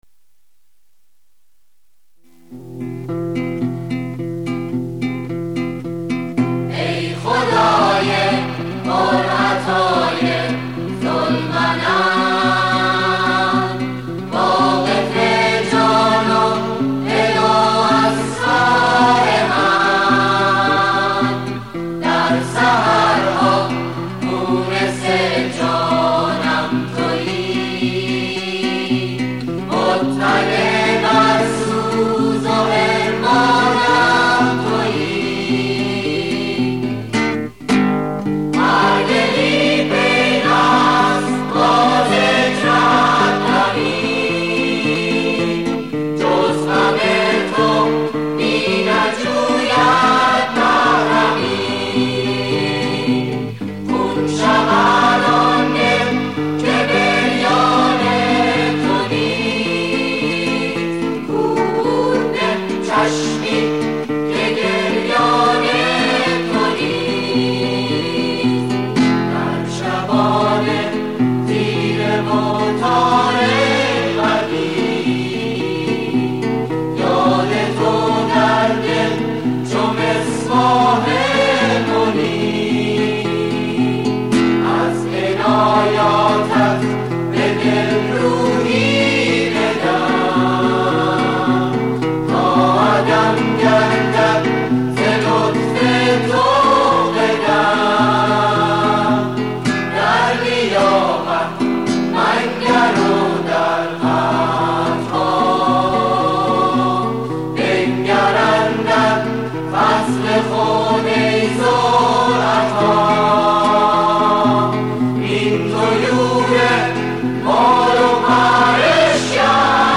سرود - شماره 7 | تعالیم و عقاید آئین بهائی